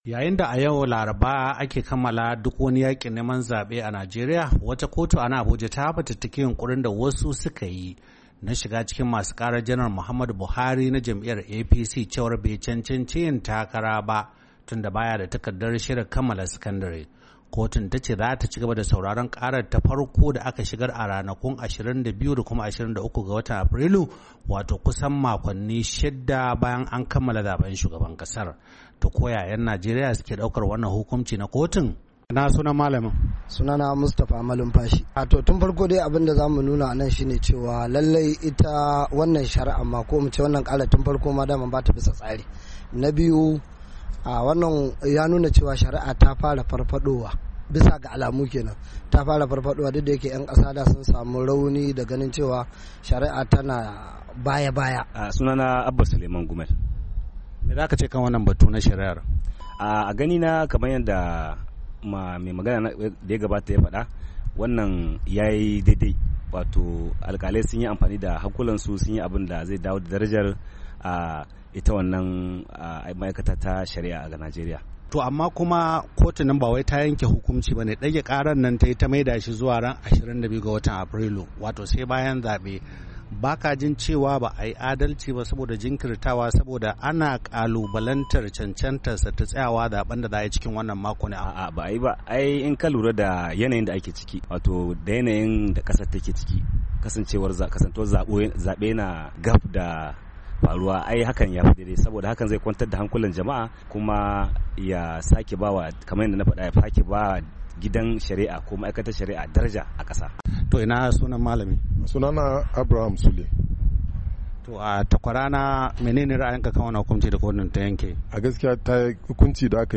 Wakilin Muryar Amurka ya zanta da wasu a Abuja domin jin ra'ayoyinsu.